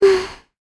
Talisha-Vox-Deny_kr.wav